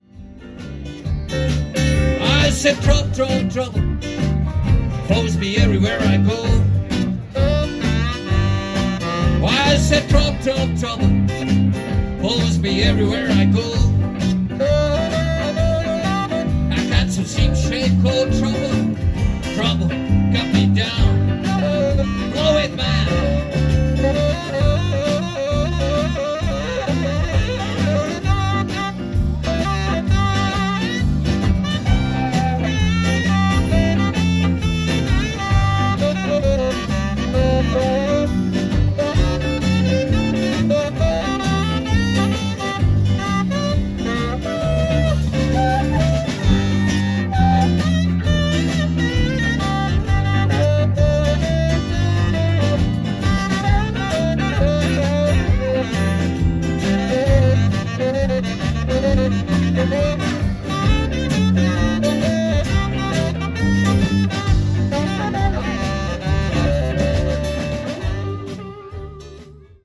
Solo
Oppligen